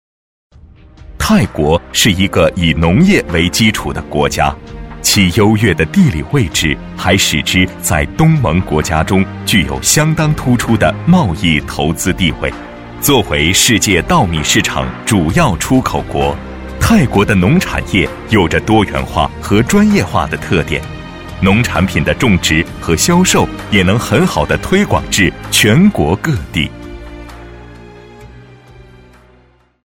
专题男90号（大气温暖风格
自然诉说 企业专题
青年男播，声音特点，年轻，磁性、大气正派。